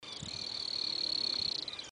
Short-billed Canastero (Asthenes baeri)
Life Stage: Adult
Location or protected area: Reserva Natural del Pilar
Condition: Wild
Certainty: Observed, Recorded vocal